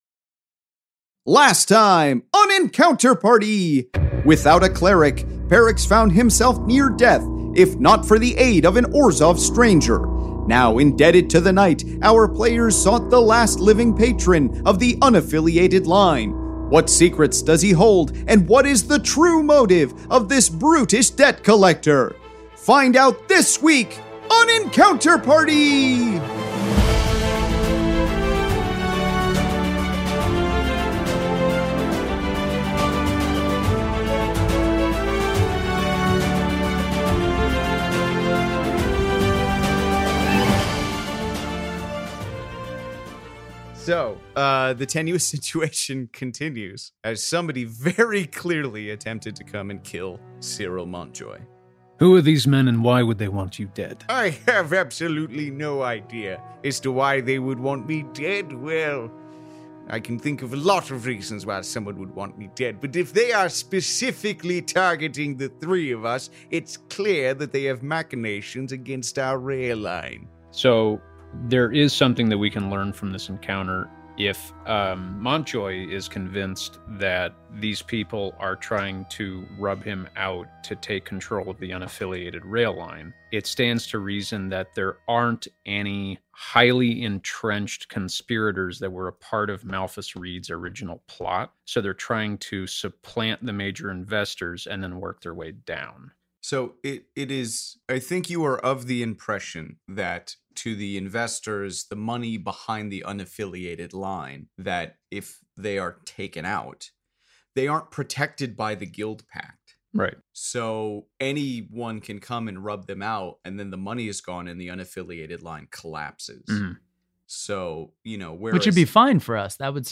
Fantasy Mystery Audio Adventure
five actors and comedians